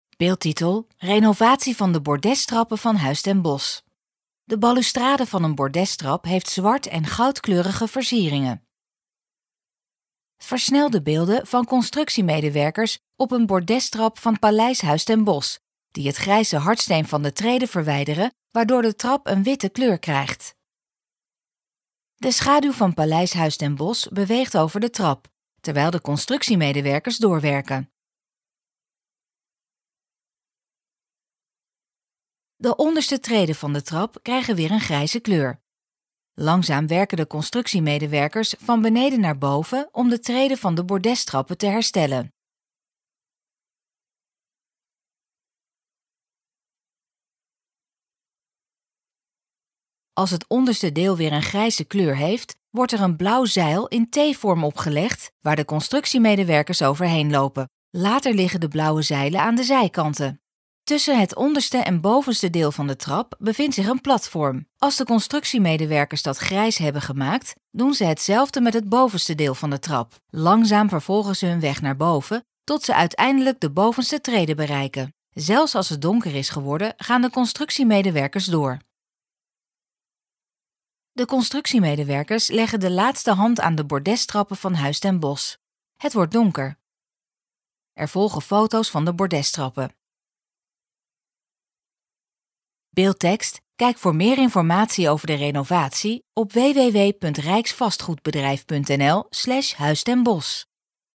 STILTE (DEZE VIDEO HEEFT GEEN GELUID)